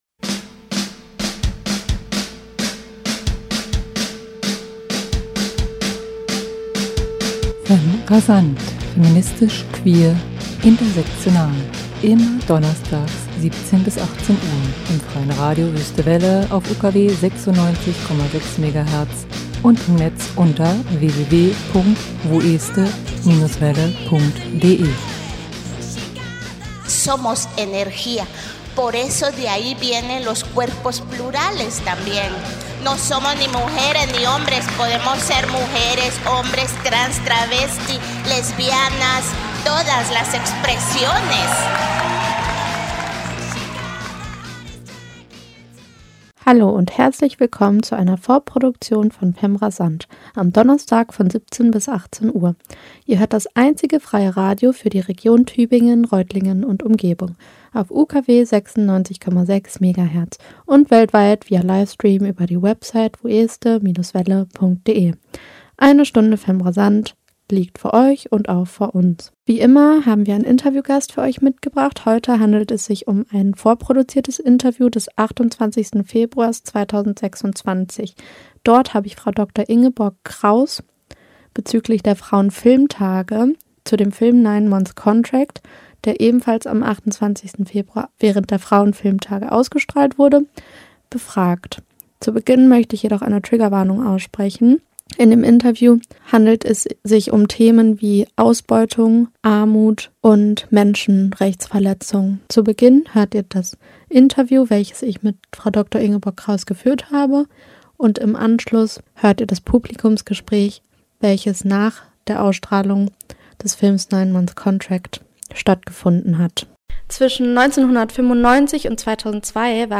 Interview und Publikumsgespräch